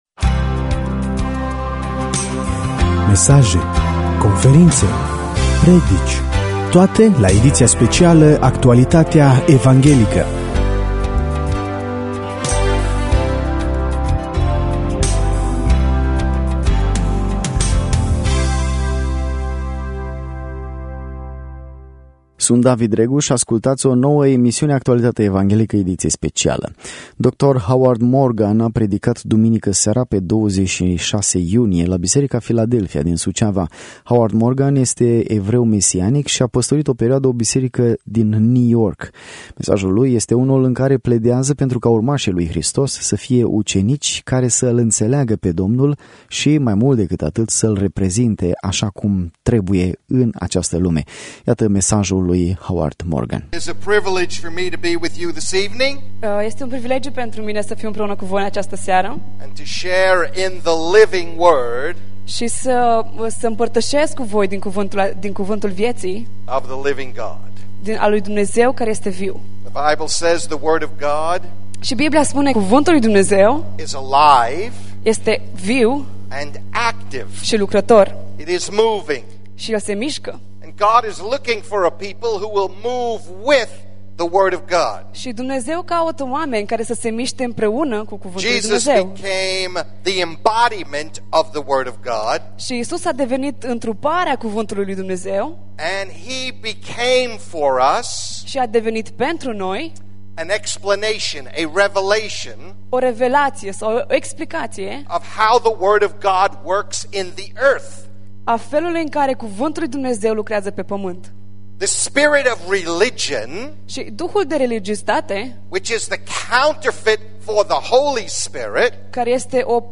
asa cum a fost prezentat duminica pe data de 26 iunie la biserica Filadelfia din Suceava.